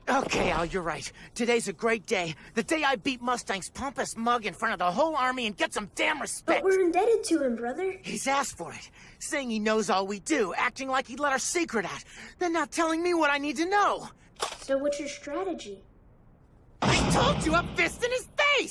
Edward and Alphonse Talking Before the Fight.wav